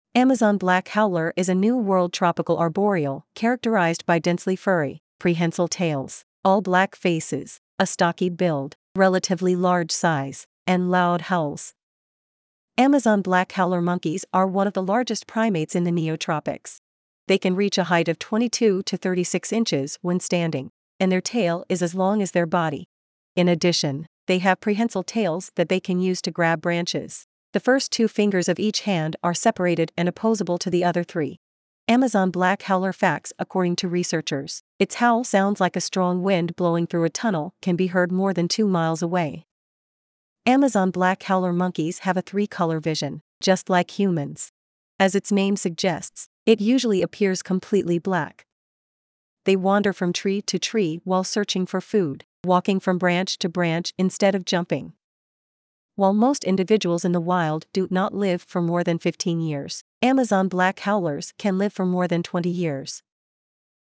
Amazon Black Howler
Amazon Black Howler[1] is a New World tropical arboreal characterized by densely furry, prehensile tails, all-black faces, a stocky build, relatively large size, and loud howls.
• According to researchers, its howl sounds like a strong wind blowing through a tunnel can be heard more than two miles away.
Amazon-Black-Howler.mp3